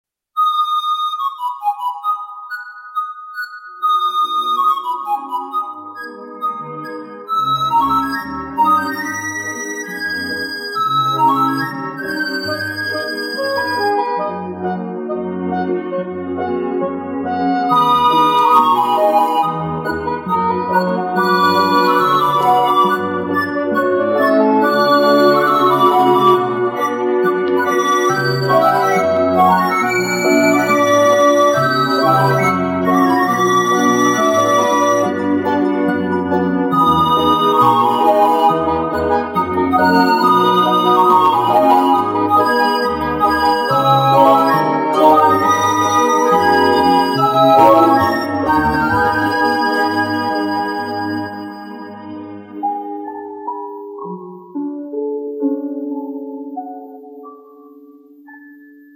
Фильм